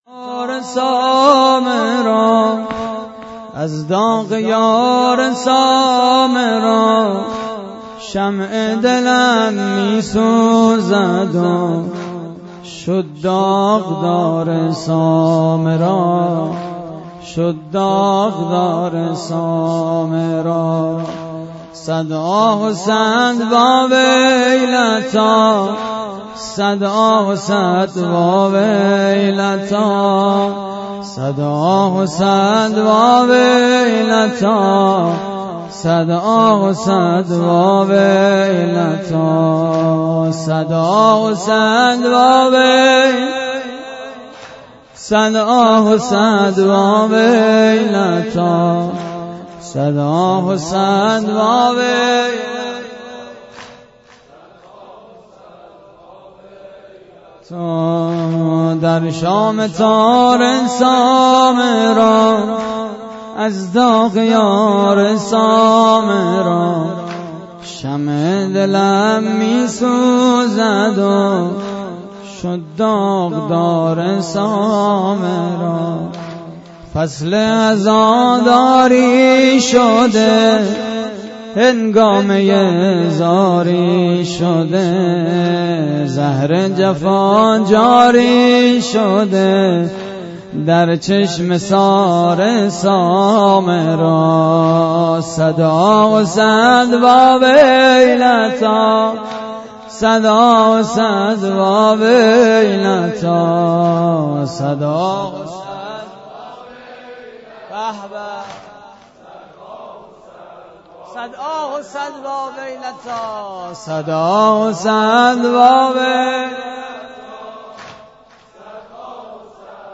مراسم عزاداری شهادت امام هادی (ع) / هیئت رهپویان آل طاها - مسجد لولاگر؛ 26 اردیبهشت 92
واحد: در شام تار سامرا